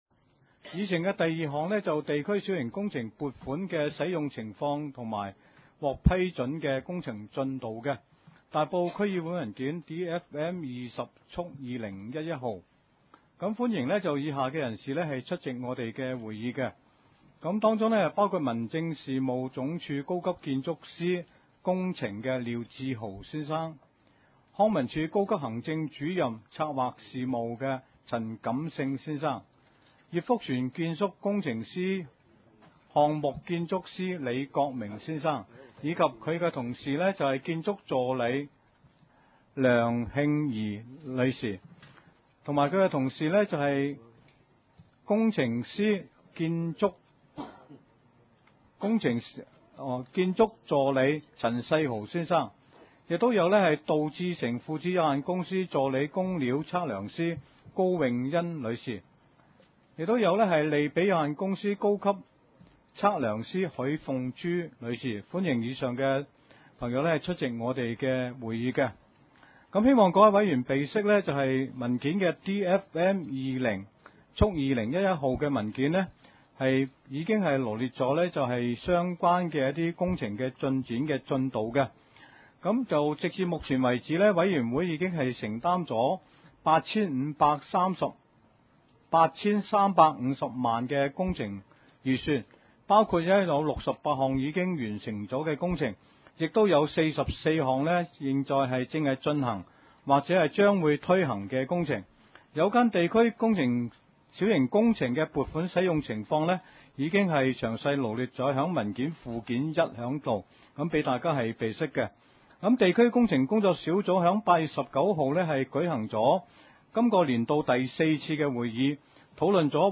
大埔區議會 地區設施管理委員會 20 11 年第五次會議 日期：2011年9月9日 (星期五) 時間：下午2時30分 地點：大埔區議會秘書處會議室 議 程 討論時間 I. 通過地區設施管理委員會 2011 年 7 月 19 日第四次會議紀錄 00:33 ( 大埔區議會文件 DFM 19/2011 號 ) II. 地區小型工程撥款的使用情況及獲批工程進度報告